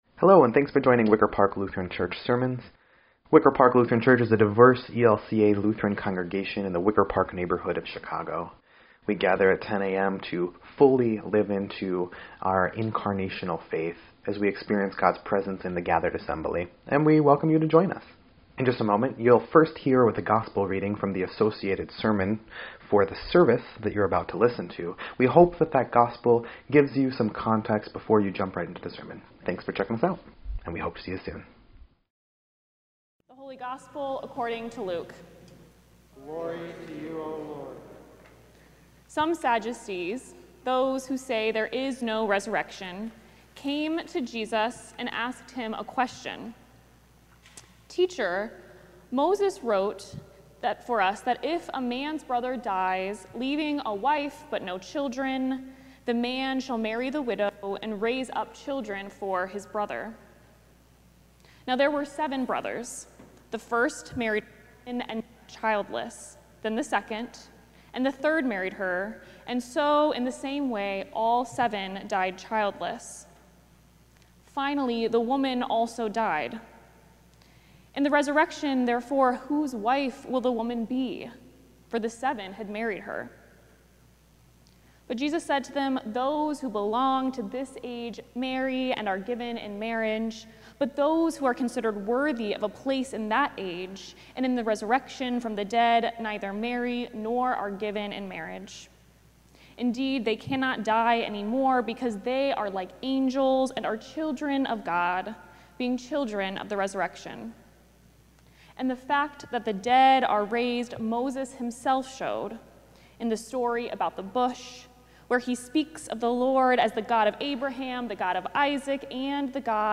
11.9.25-Sermon_EDIT.mp3